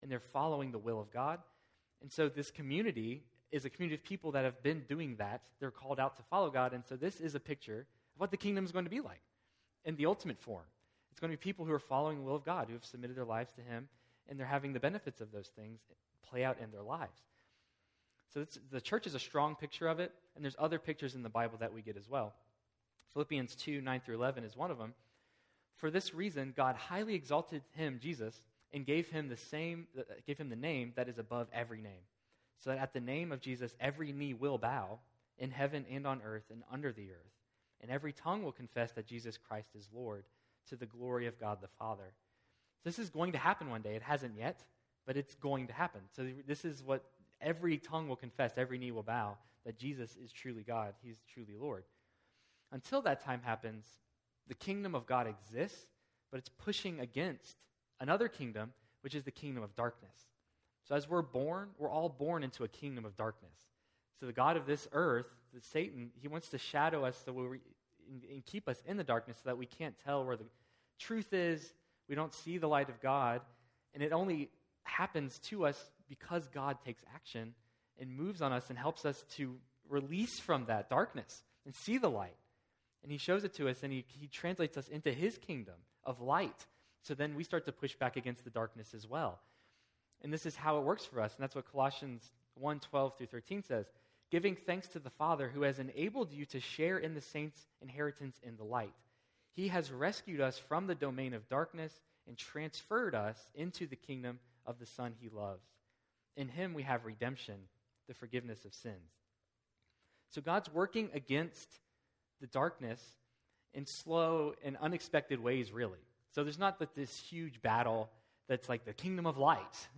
This sermon explores the small origins and big outcomes of the kingdom of God. Through the seed parables we will see that even small acts of faith contribute to a larger overall kingdom outcome.